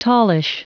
Prononciation du mot tallish en anglais (fichier audio)
Prononciation du mot : tallish